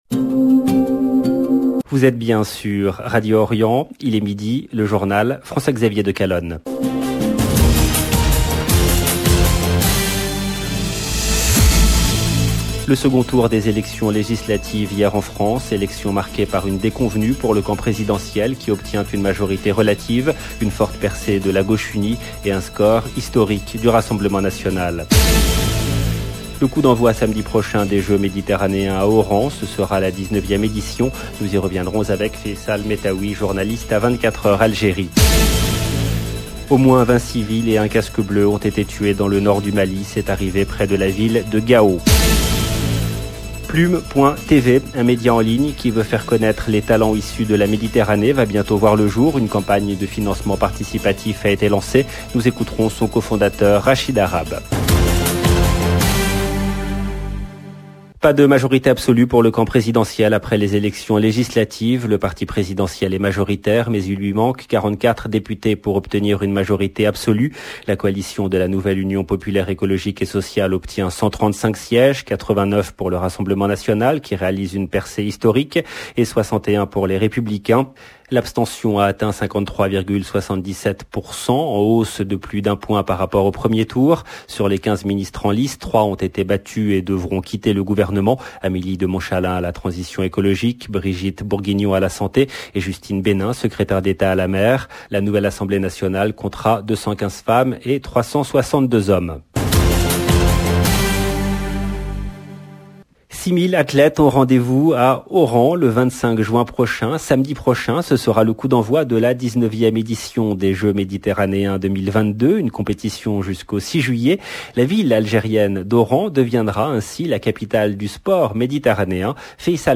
LE JOURNAL EN LANGUE FRANCAISE DE MIDI DU 20/06/22